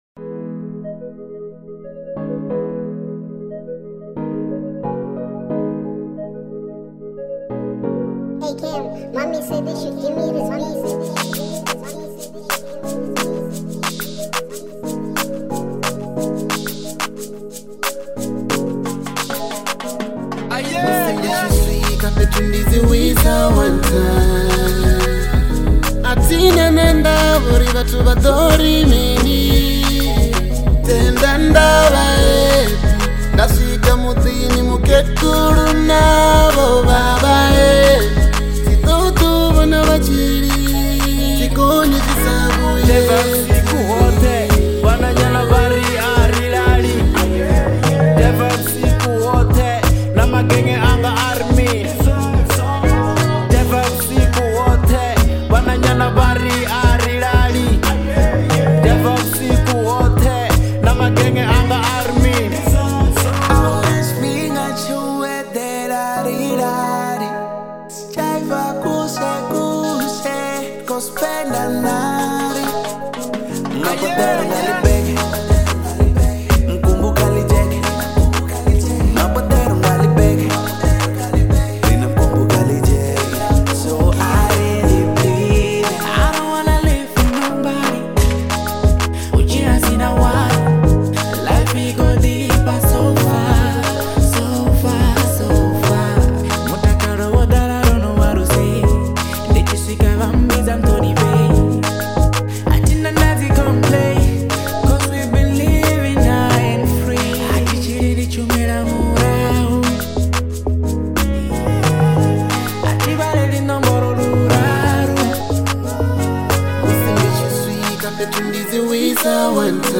04:27 Genre : Venrap Size